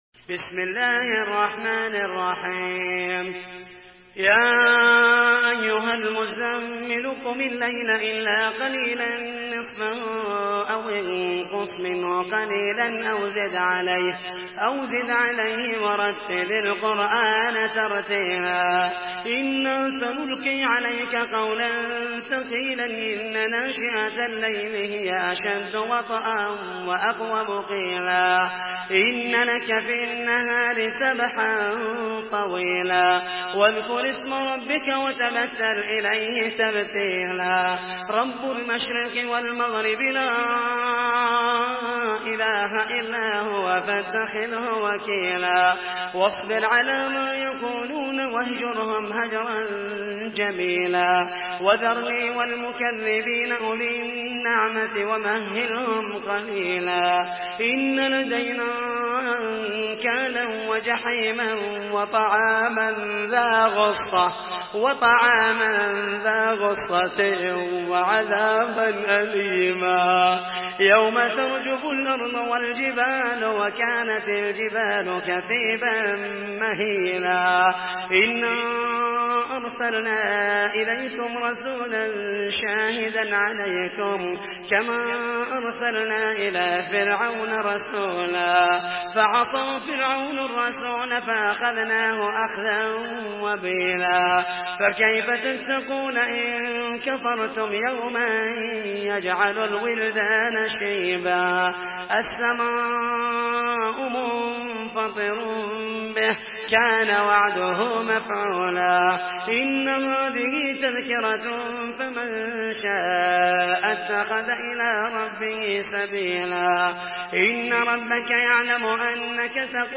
سورة المزمل MP3 بصوت محمد المحيسني برواية حفص
مرتل